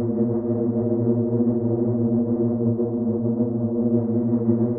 SS_CreepVoxLoopA-01.wav